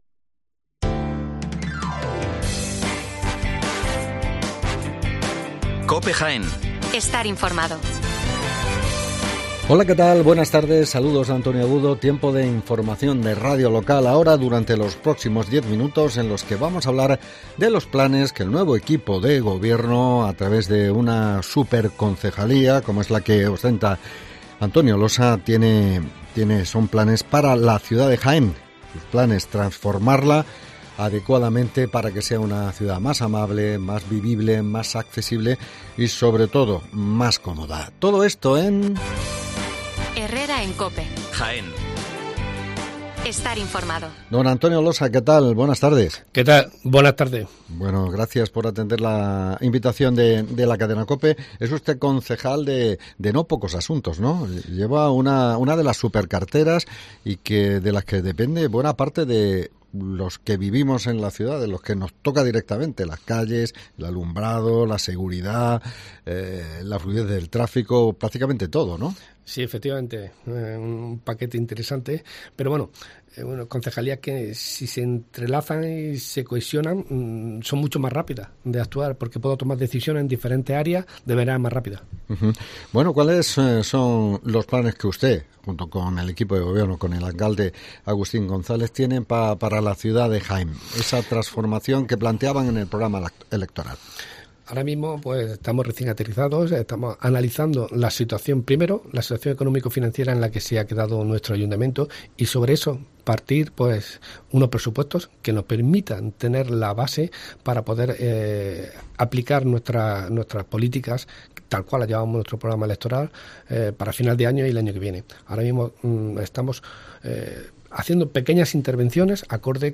Entrevista con el concejal del PP de Jaén, Antonio Losa